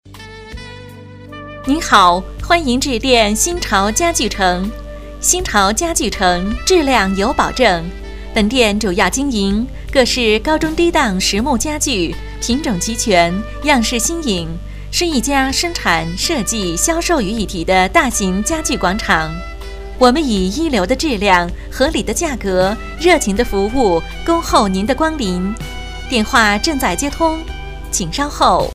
电话宣传彩铃试听